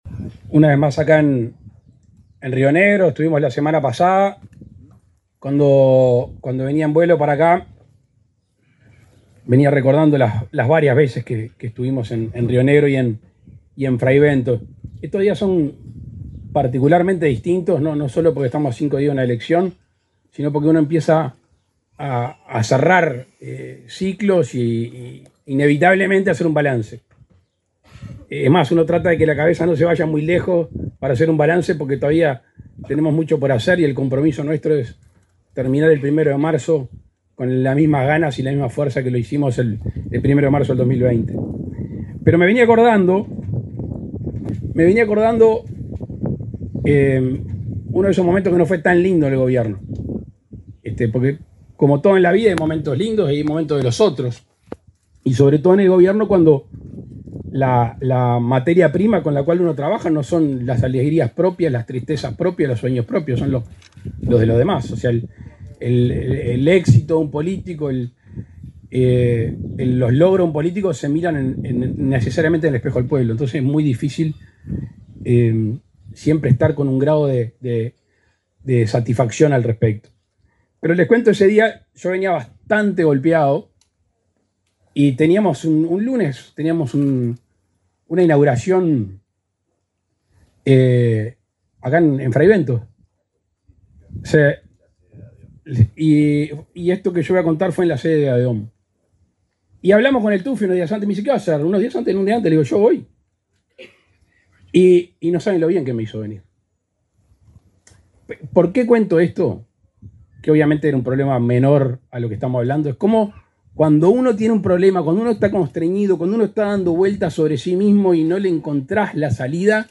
Palabras del presidente Luis Lacalle Pou
Palabras del presidente Luis Lacalle Pou 22/10/2024 Compartir Facebook X Copiar enlace WhatsApp LinkedIn El presidente de la República, Luis Lacalle Pou, participó en Fray Bentos, departamento de Río Negro, en la presentación de las instalaciones del centro Ni Silencio Ni Tabú.